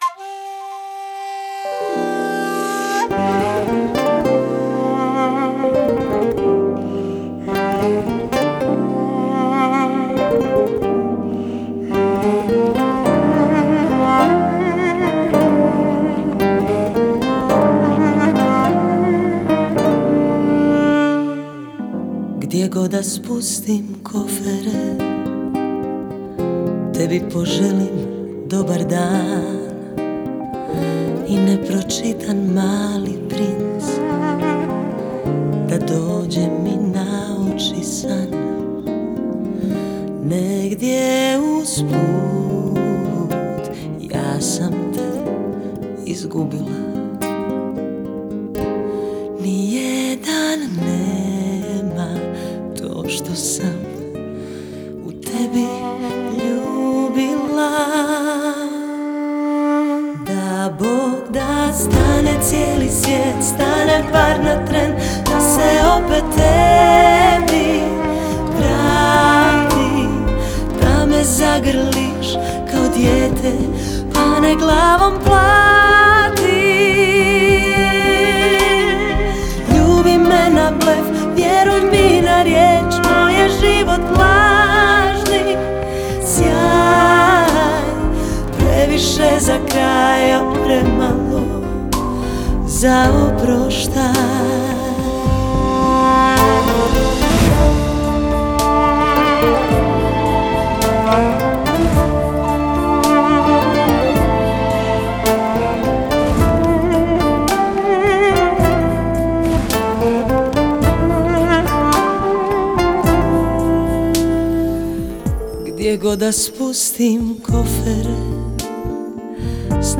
Популярная хорватская поп-певица.